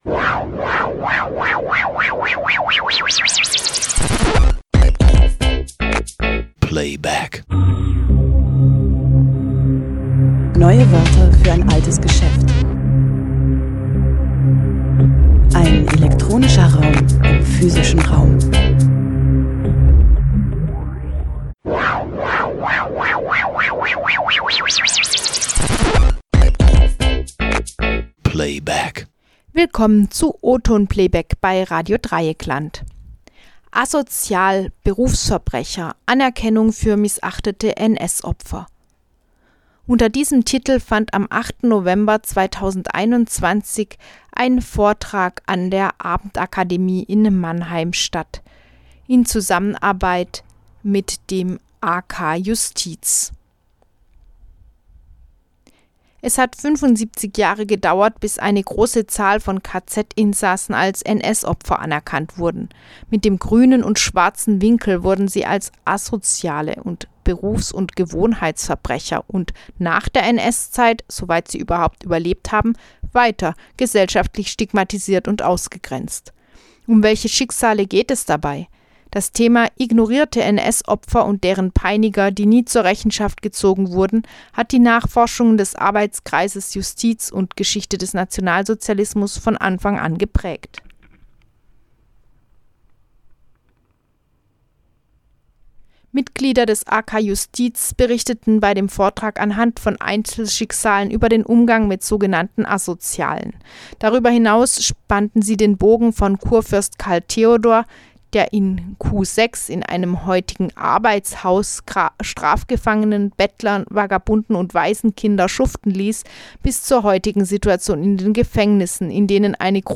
"Asozial", "Berufsverbrecher" - Anerkennung für missachtete NS-OpferVortrag am 8.